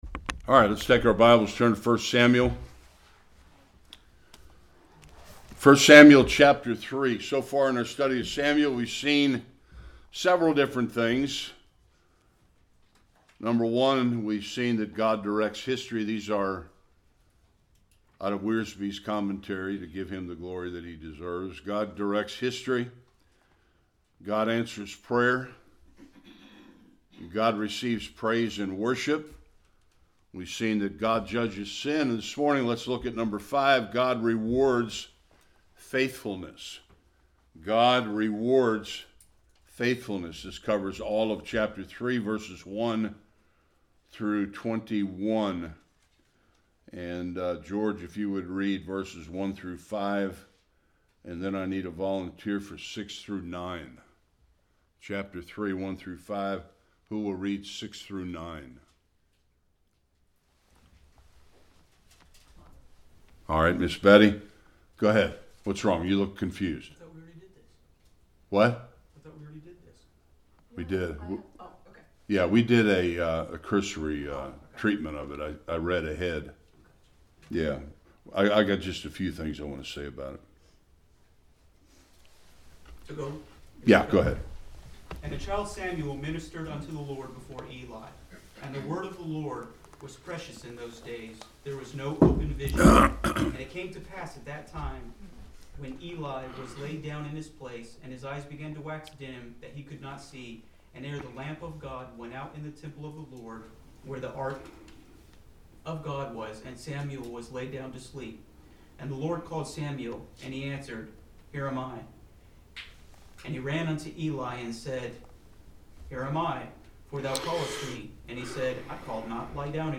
1-21 Service Type: Sunday School The call of young Samuel who was Israel’s last judge and also a prophet and priest.